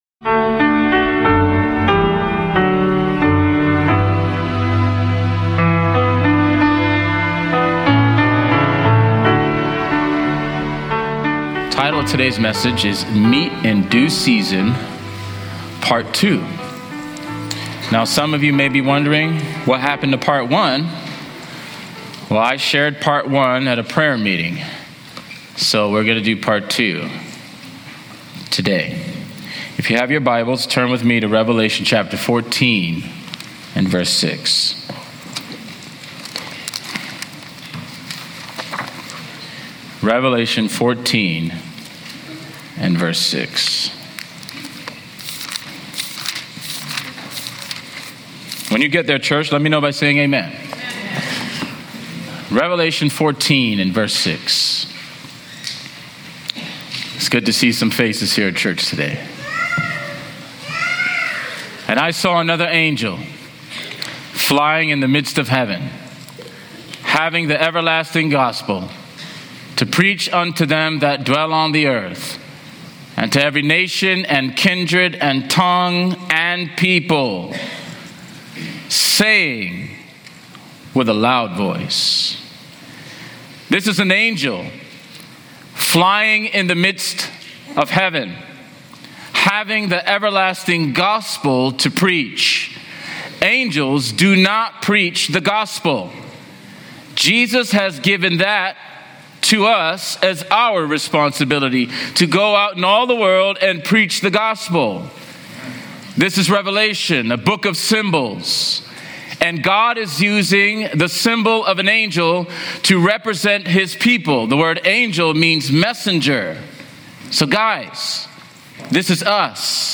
As messengers of the gospel, we are called to share God’s truth with urgency, compassion, and authenticity. This powerful sermon explores the symbolism of angelic representation in Revelation, the urgency of evangelism, the connection between healing and the gospel, and the call to fear God, glorify Him, and live with the awareness of His judgment.